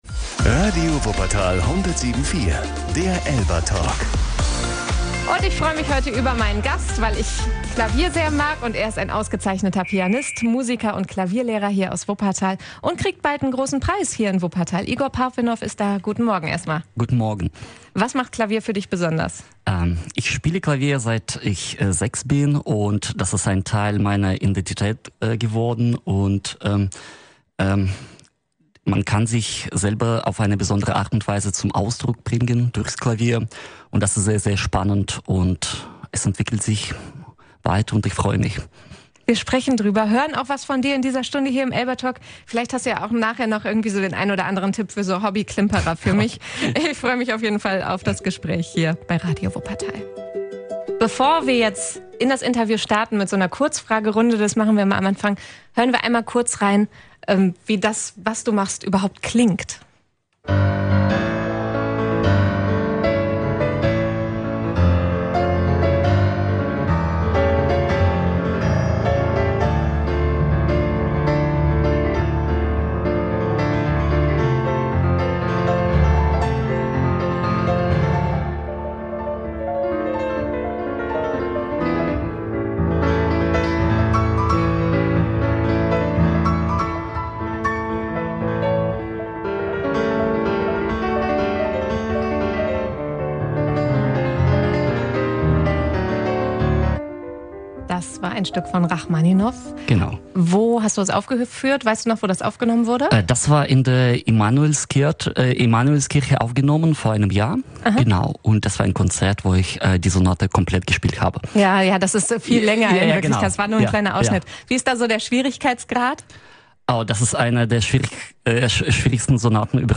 Außerdem spricht er über seinen großen Traum: ein eigener Flügel. Hört euch das ganze Interview hier an!